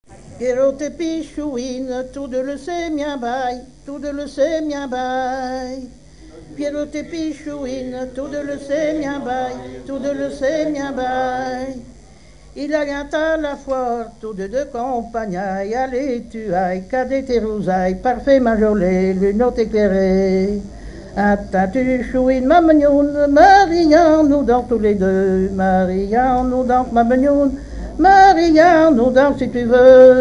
circonstance : fiançaille, noce
Genre laisse
Catégorie Pièce musicale inédite